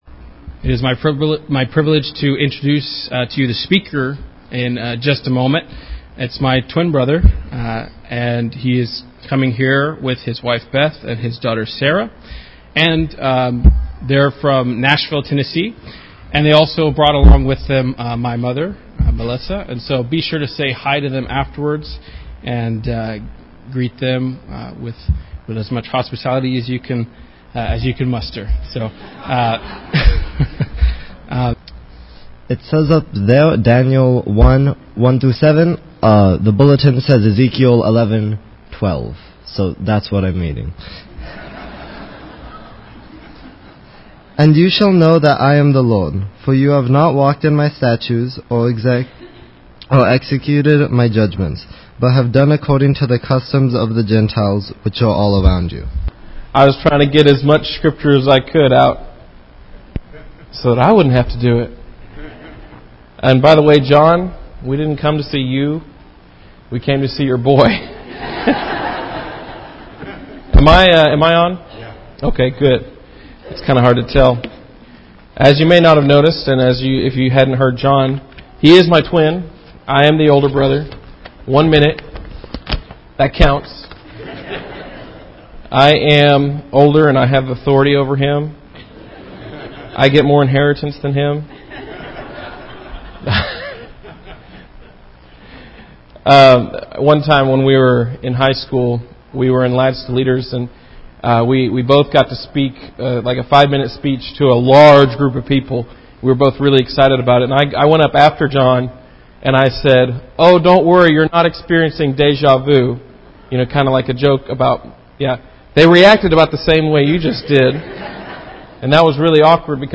This week we have a special guest speaker